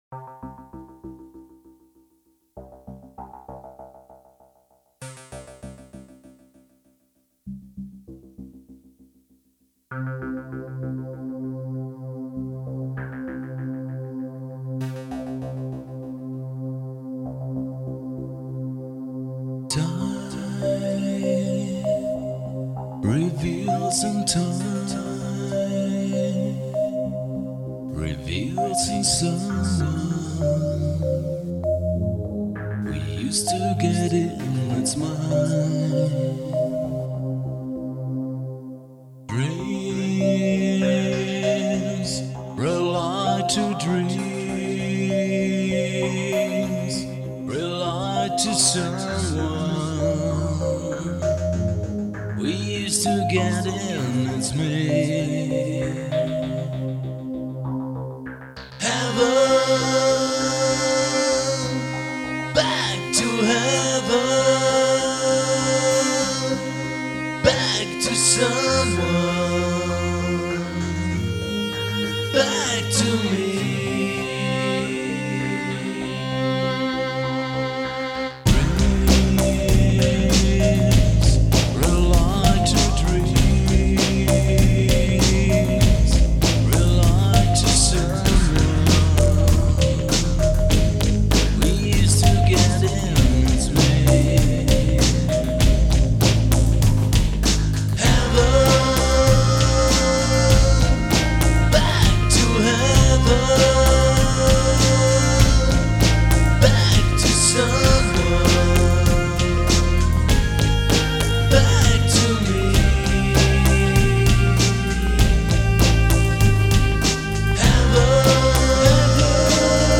elektronische Klangwelt
ursprüngliche Demo Fassung